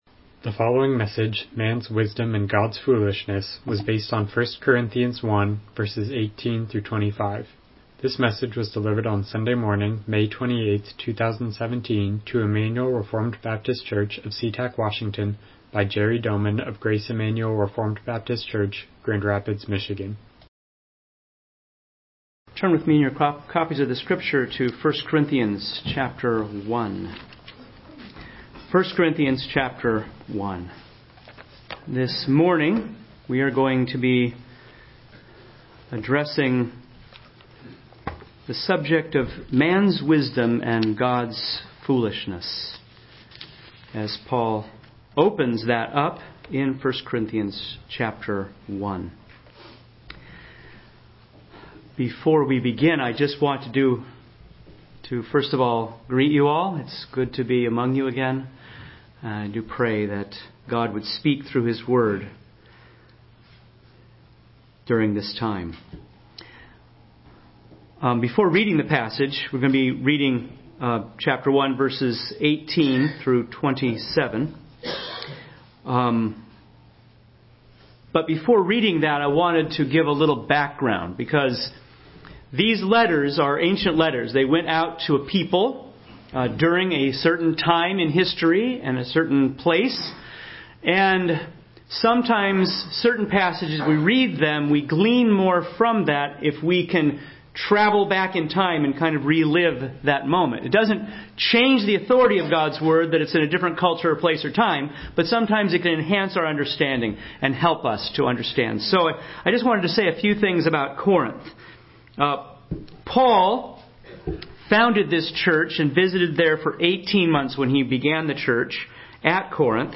Studies in First Corinthians Passage: 1 Corinthians 1:18-25 Service Type: Morning Worship « How Do We Know Anything?